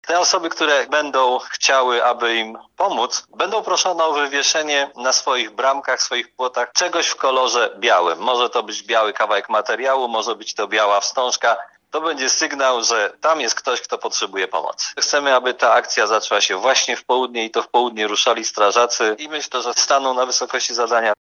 – W nagłych przypadkach, gdy potrzebna jest jakaś pomoc, bądź wsparcie psychologiczne, wystarczy w odpowiedni sposób dać znać – mówi prezydent Tarnobrzega, Dariusz Bożek.